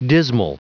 Prononciation du mot dismal en anglais (fichier audio)
Prononciation du mot : dismal